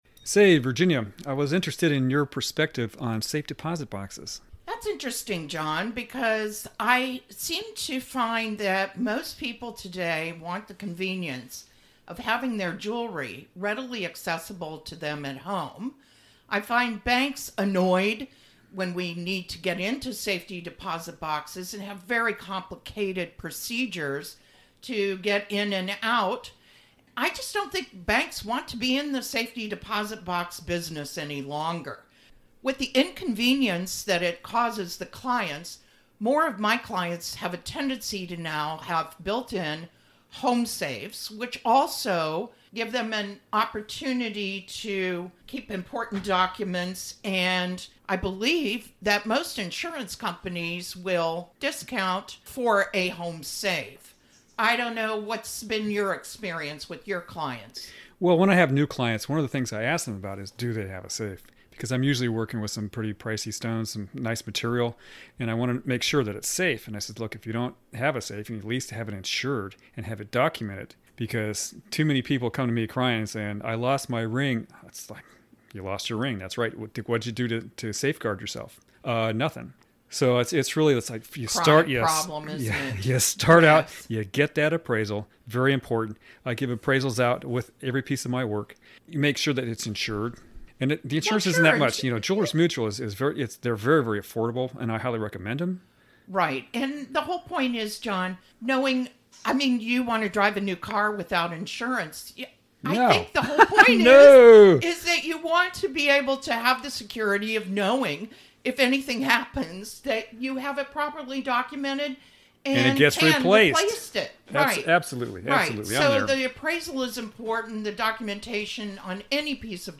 The podcasts touch on the rich history of jewelry from two industry professionals who share their knowledge and experiences.
Colleagues working together giving insight